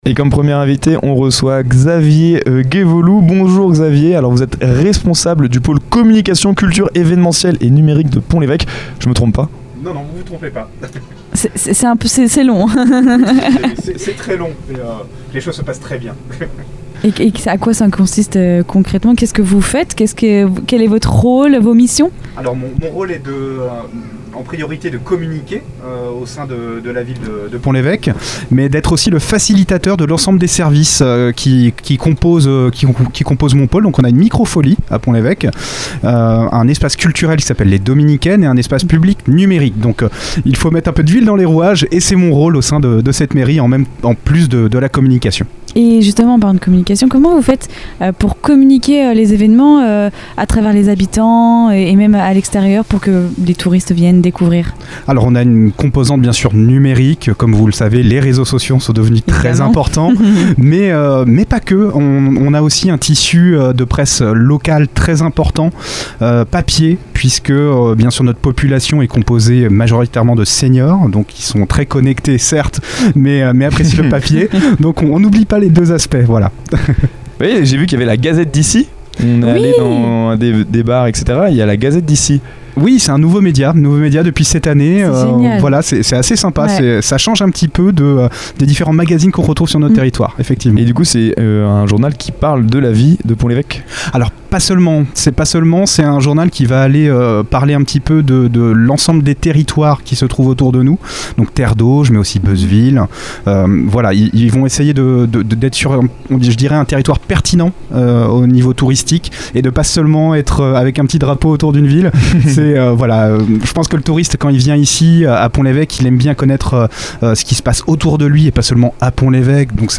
Un échange convivial et passionnant qui met en lumière une destination authentique, où tradition et modernité se rencontrent au cœur de la Normandie.